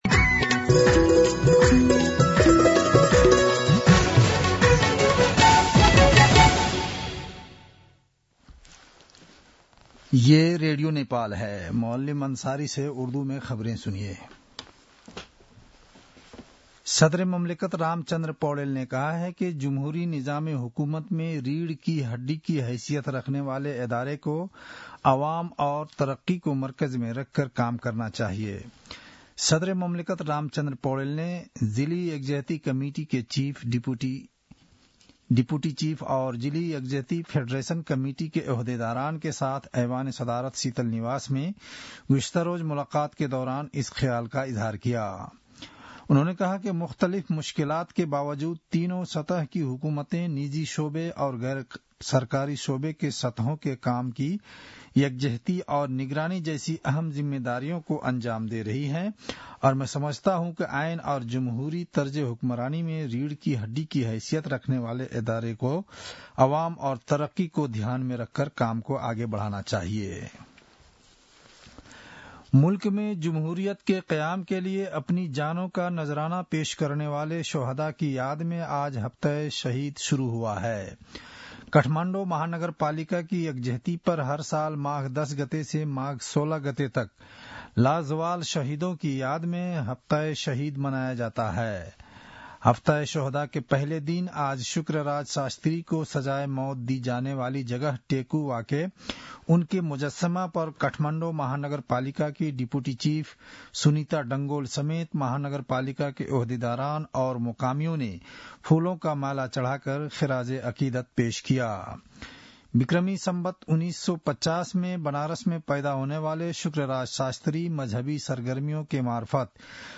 उर्दु भाषामा समाचार : ११ माघ , २०८१